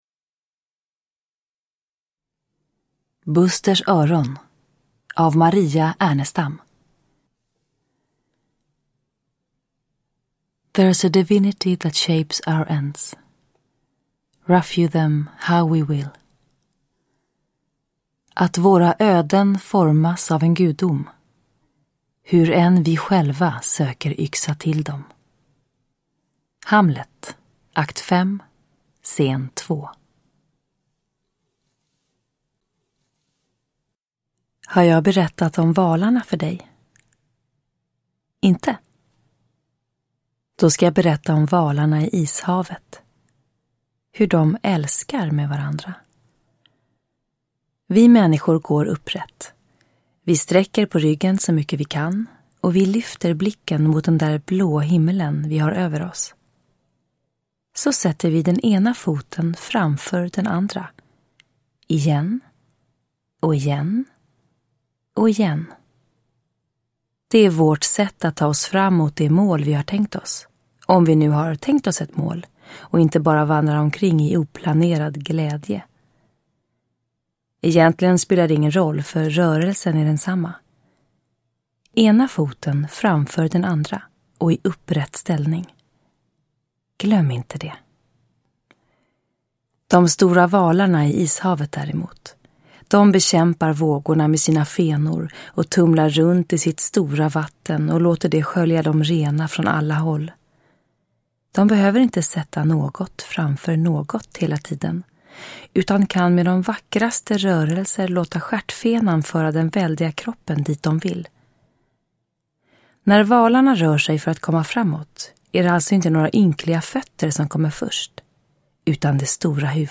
Nedladdningsbar Ljudbok
Berättare